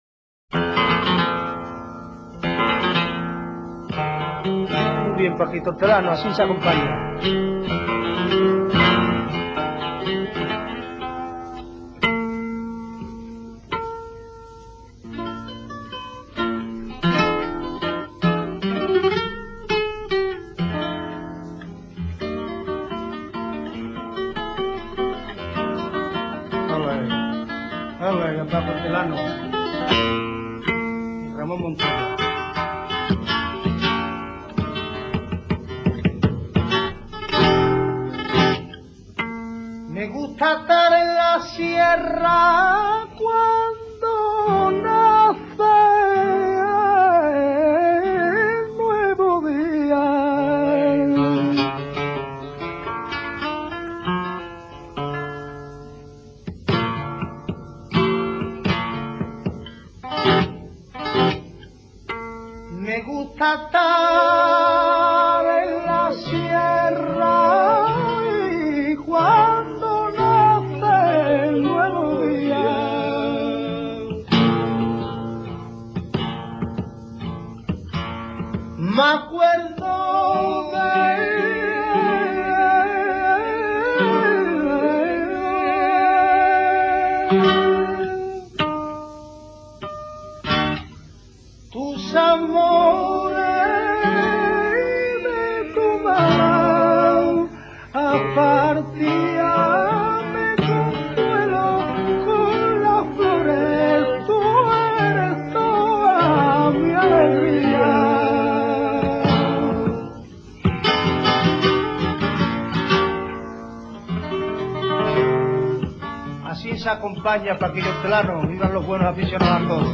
Sonidos y Palos del Flamenco
colombiana.mp3